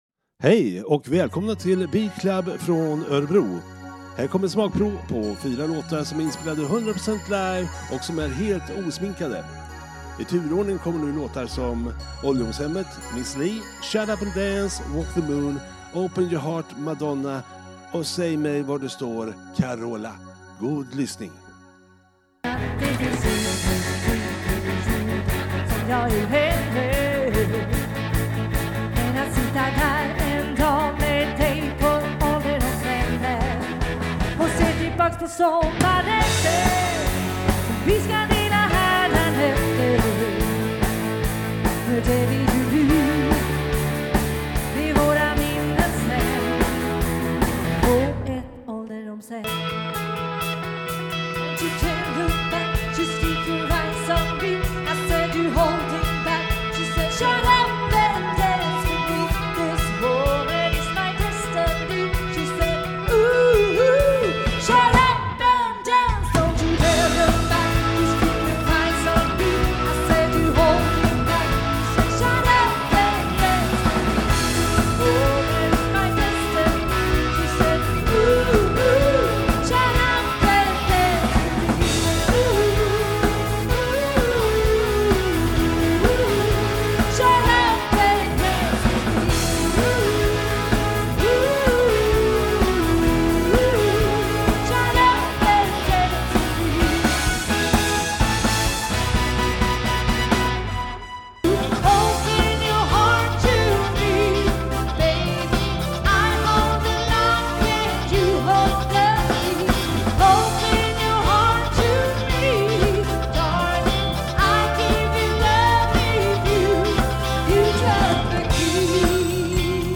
Coverband partyband bröllop fest